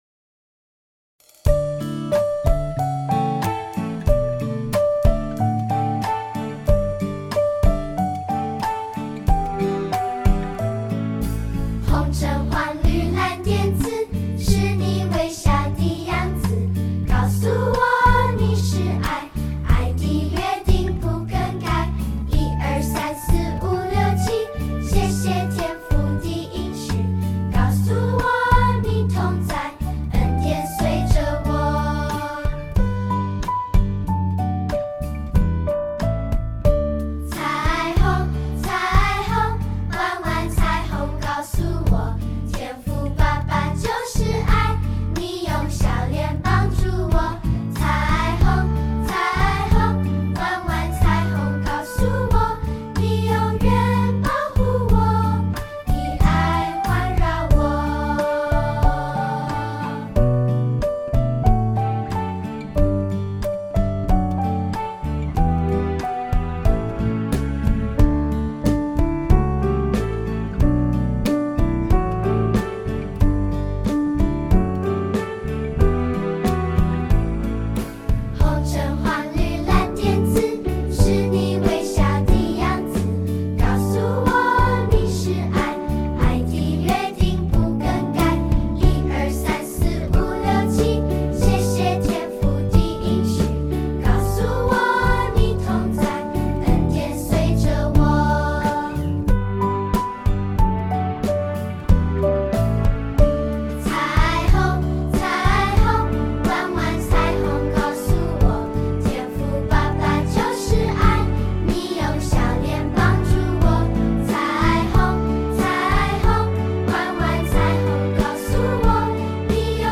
视频里有动作演示，音频里歌会自动重复三遍。
诗歌第一遍